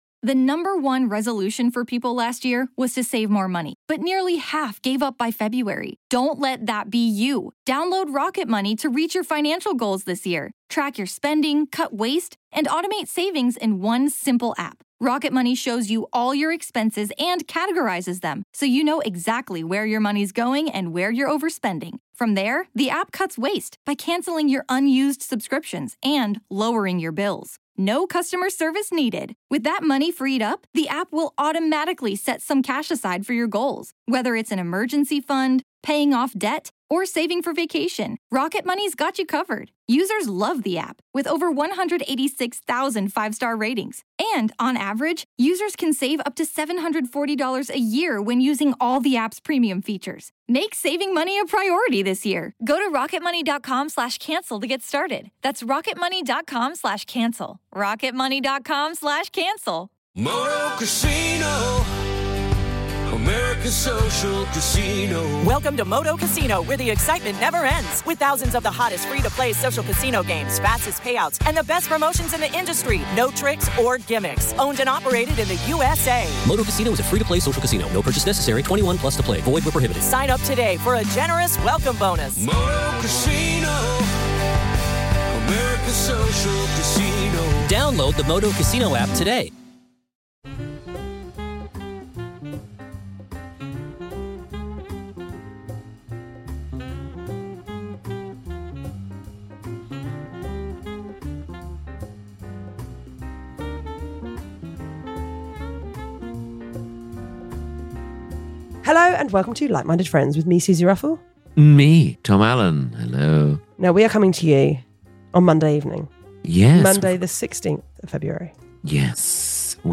Comedians and dearest pals Tom Allen and Suzi Ruffell chat friendship, love, life, and culture... sometimes...